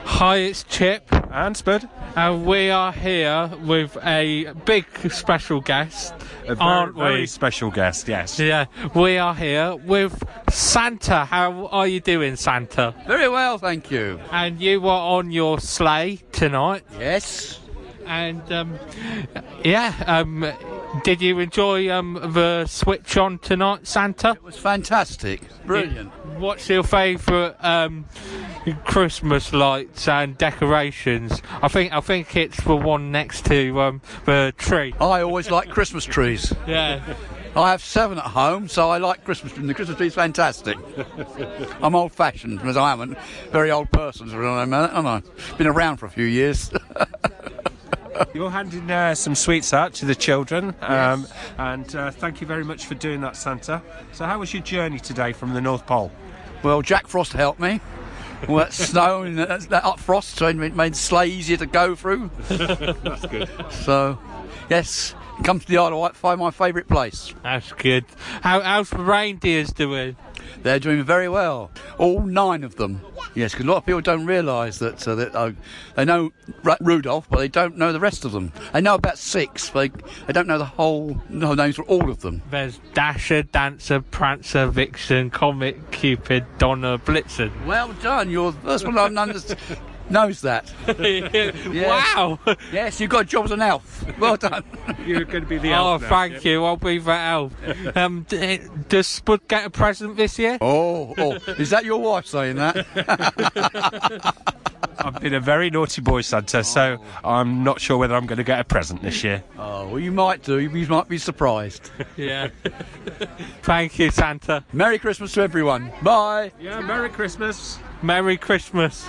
Santa Interview (long Lane Christmas Lights 2023)